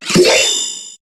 Cri de Dimoclès dans Pokémon HOME.